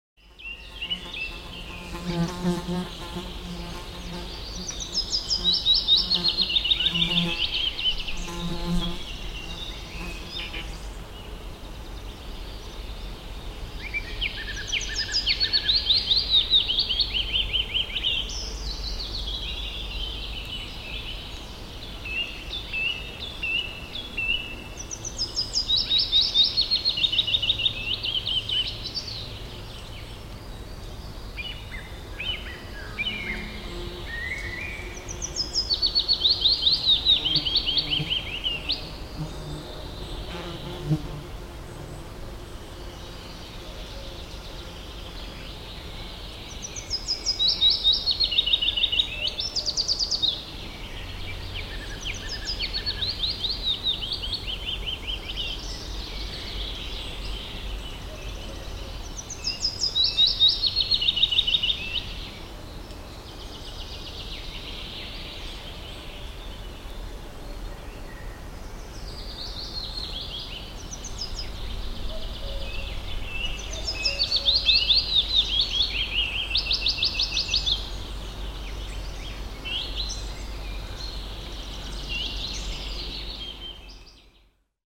Bird sounds from the sound of bees - Eğitim Materyalleri - Slaytyerim Slaytlar